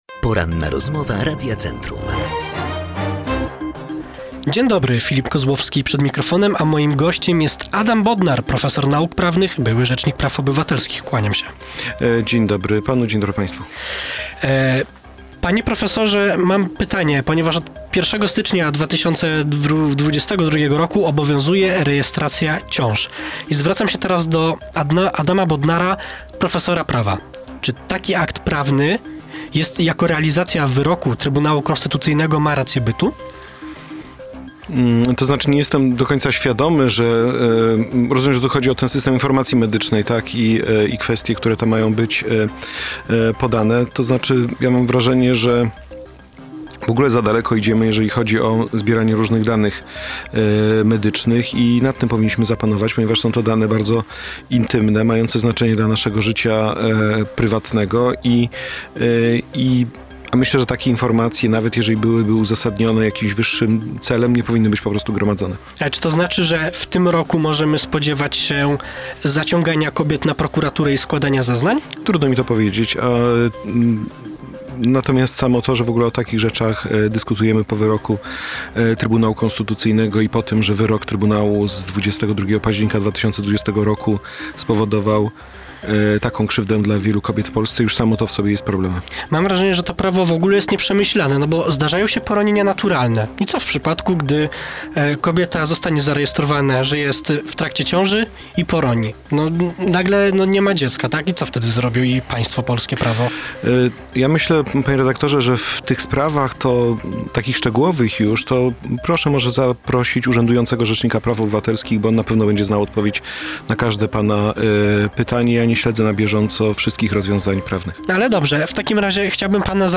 Według Adama Bodnara, byłego rzecznika praw obywatelskich i gościa dzisiejszej Porannej Rozmowy Radia Centrum, wyrok Trybunału Konstytucyjnego w sprawie aborcji jest naruszeniem praw kobiet.